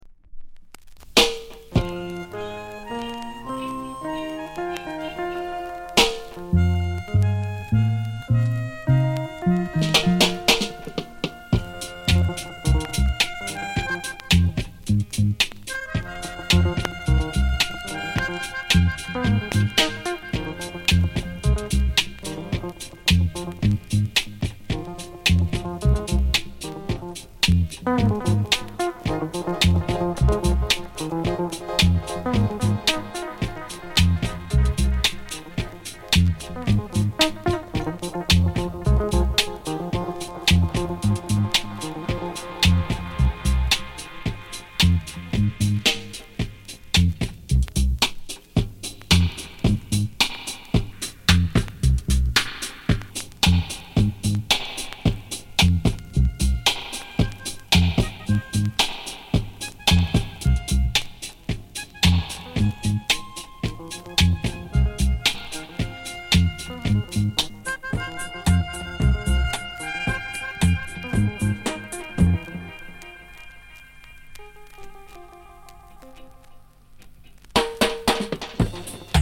JA FUNK～RARE GROOVE！